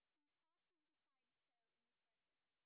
sp19_white_snr10.wav